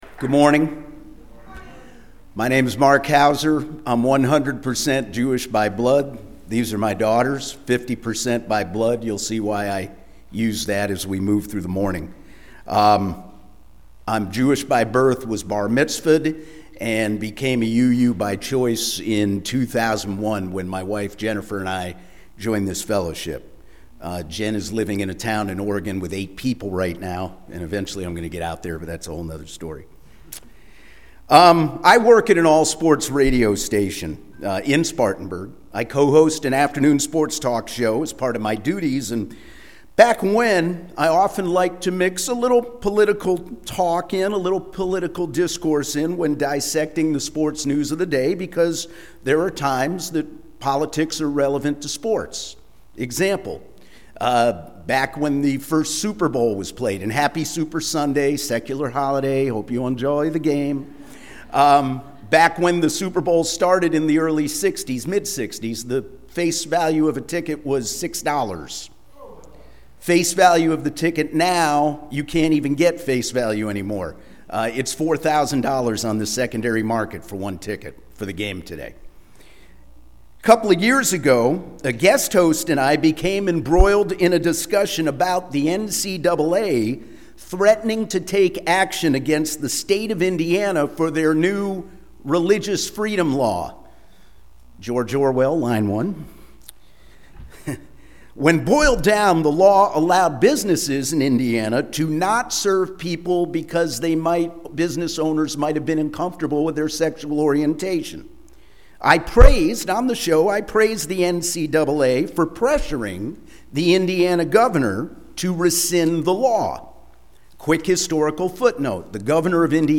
This week’s sermon features an interview/discussion